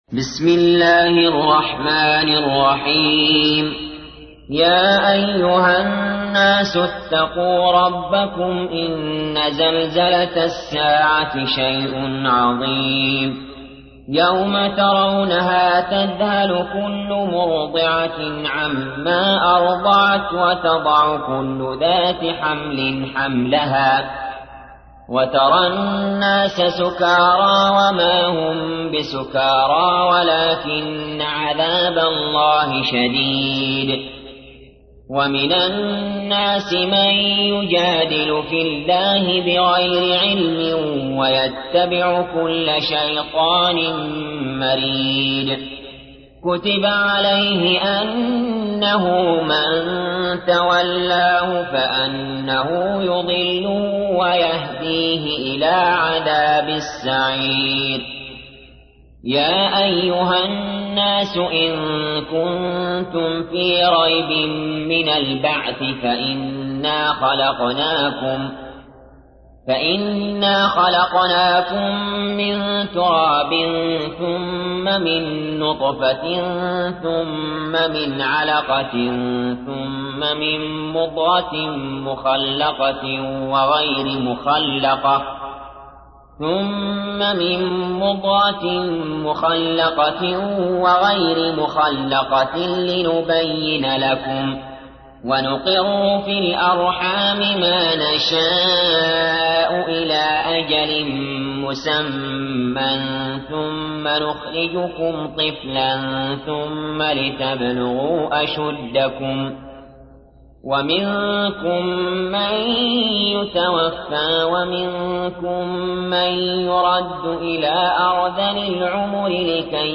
تحميل : 22. سورة الحج / القارئ علي جابر / القرآن الكريم / موقع يا حسين